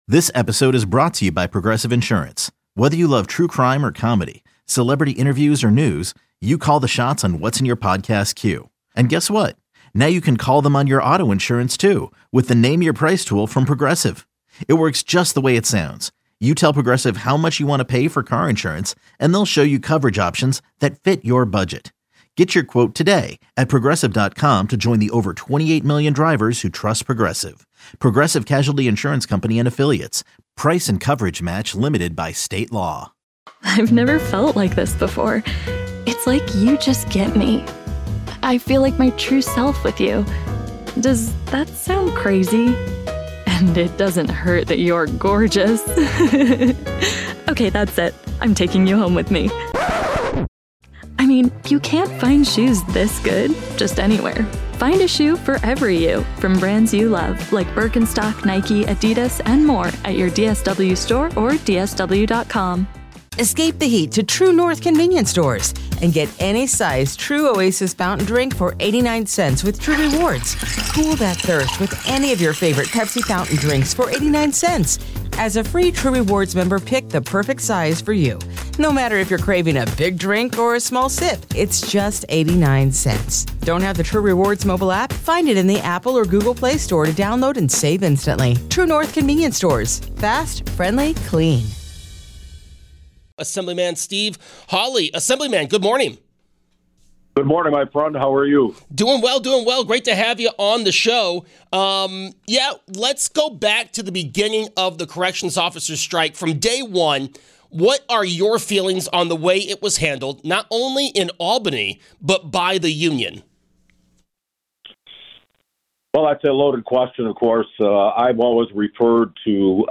Assemblyman Steve Hawley joins the show to discuss the Corrections Officers Strike.